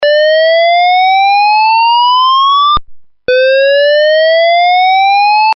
Sirena electrónica